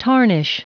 Prononciation du mot tarnish en anglais (fichier audio)
Prononciation du mot : tarnish